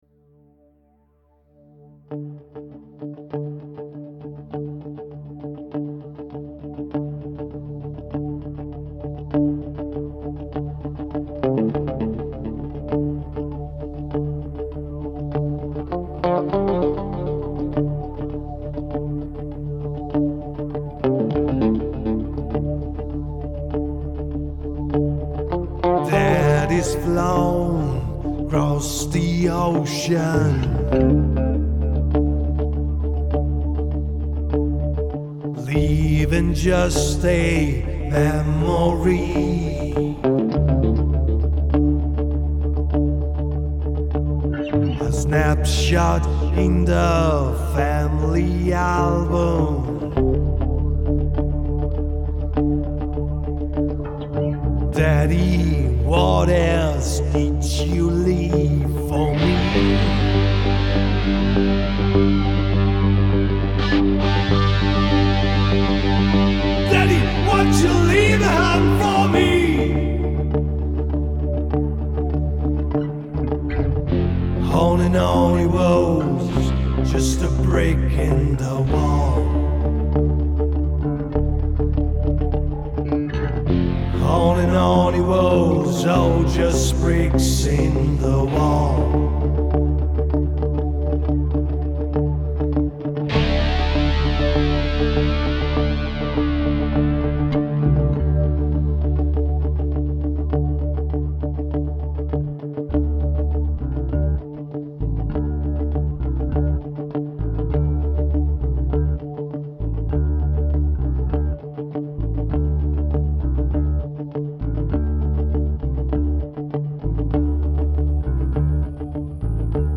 (NO GUIT) 100bpm